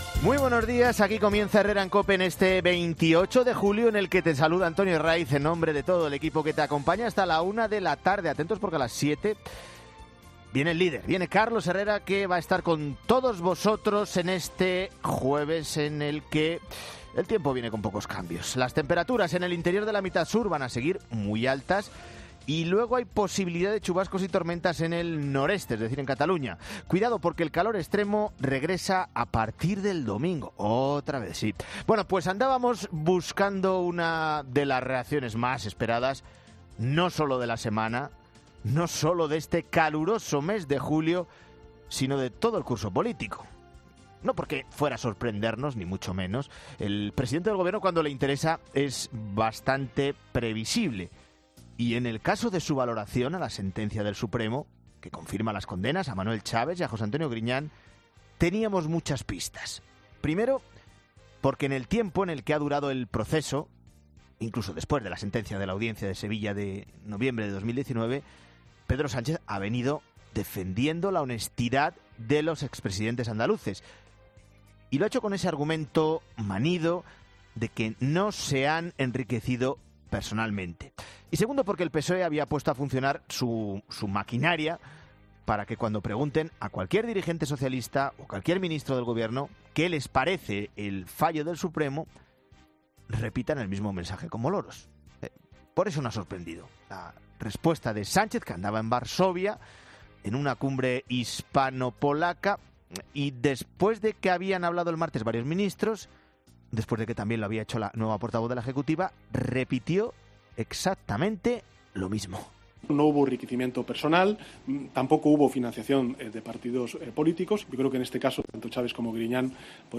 presentador de 'Herrera en COPE'